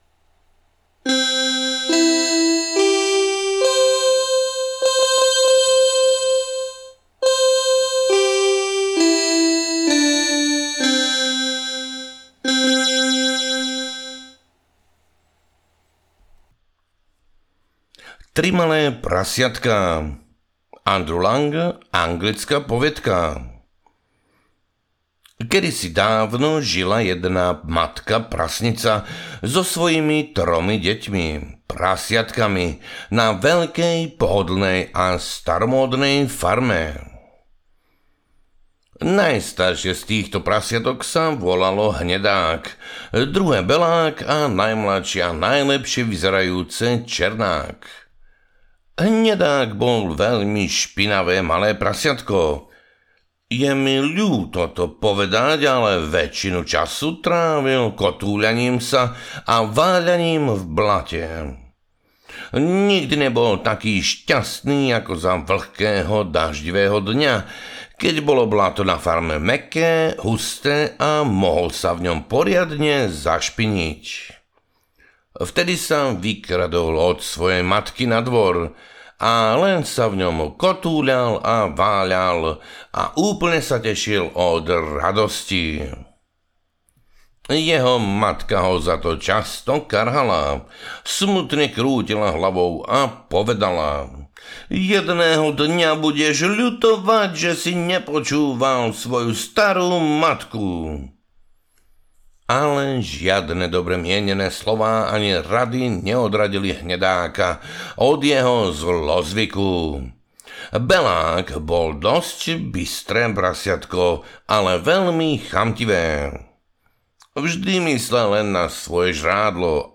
Poviedky a vety 1: Slovenské vydanie audiokniha
Ukázka z knihy